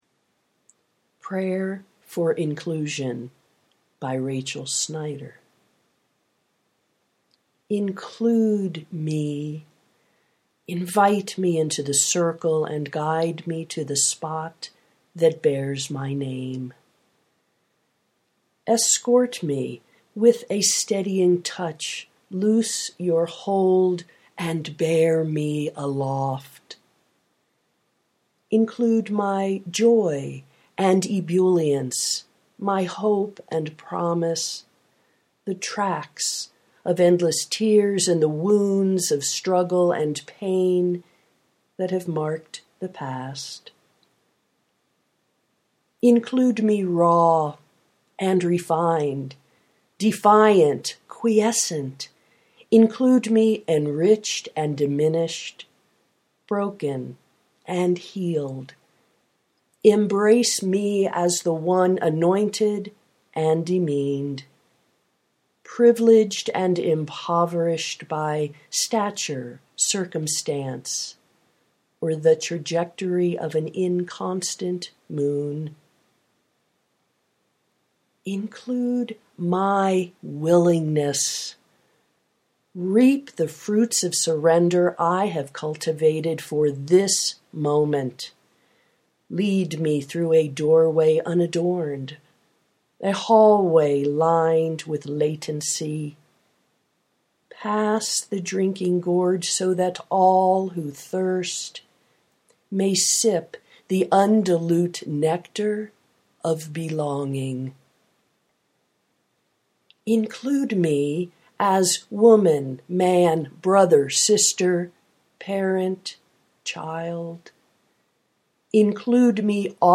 prayer for inclusion (audio poetry 3:22)